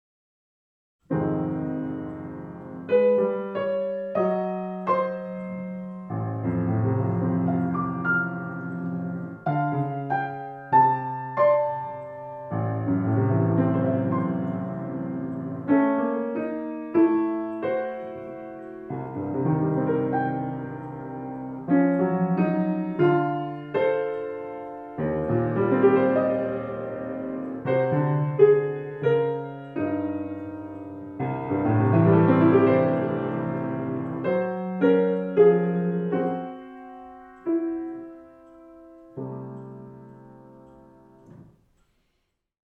Musik zum Mantra 22 V — angespannt